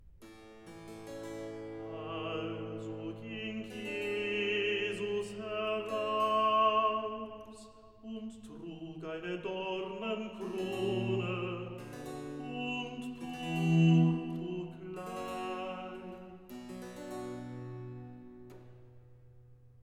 05 - Recitativo evangelist Also ging Jesus neraus